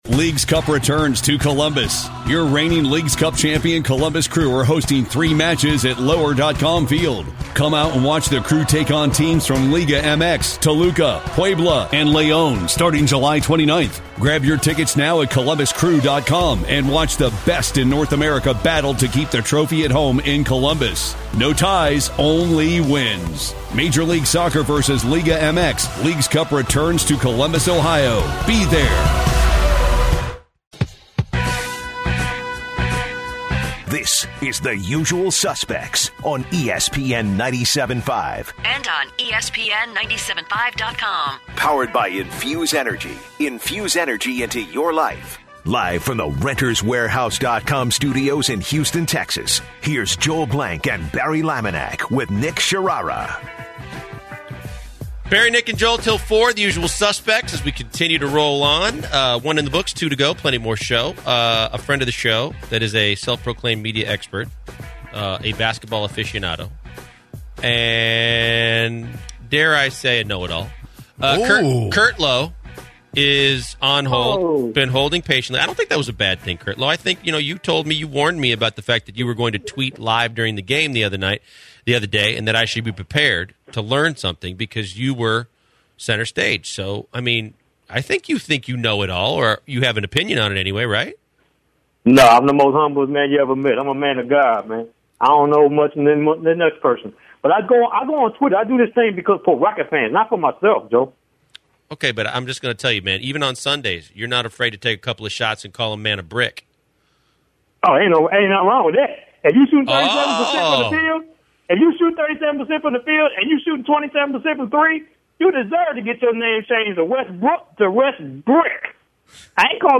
In hour two, the guys go into the upcomng NFL Draft and who are the best player teams should look at. Then, the guys take calls on the NFL Draft.